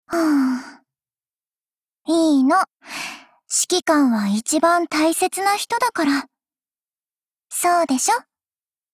贡献 ） 协议：Copyright，其他分类： 分类:碧蓝航线:星座语音 您不可以覆盖此文件。